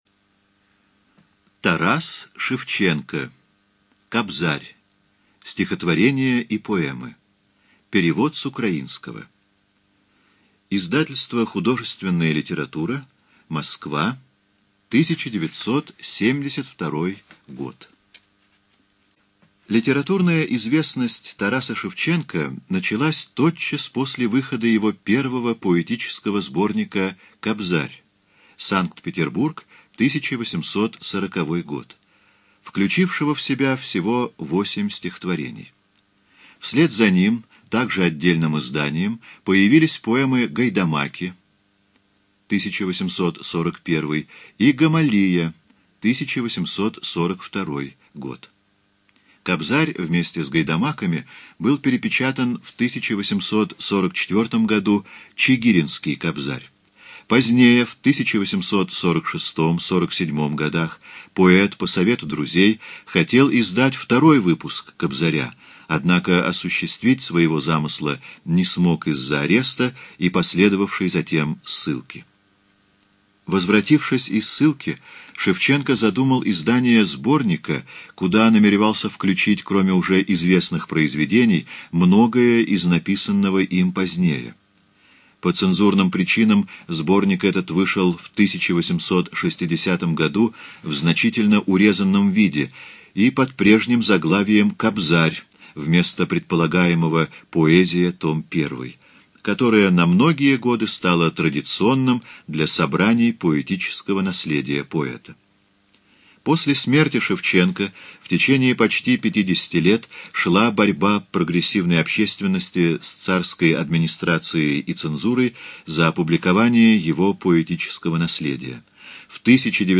ЖанрПоэзия
Студия звукозаписиЛогосвос